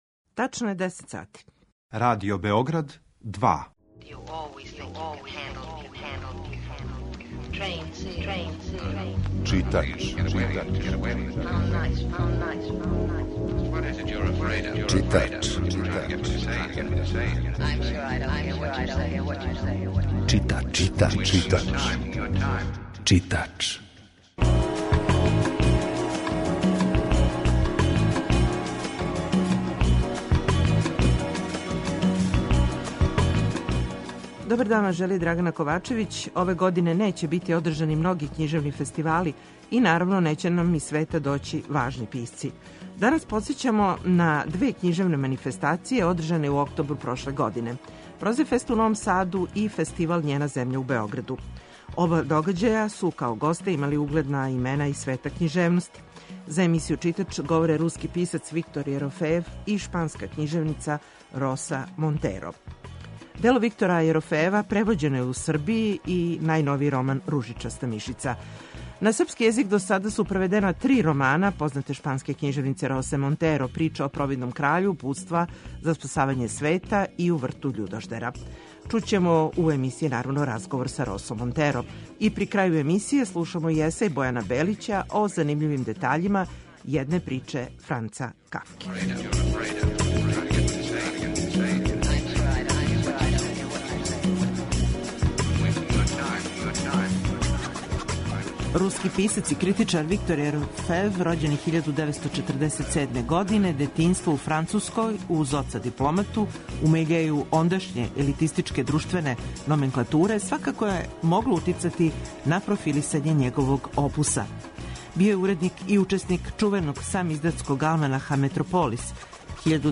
За емисију Читач говоре руски писац Виктор Јерофејев и шпанска књижевница Роса Монтеро.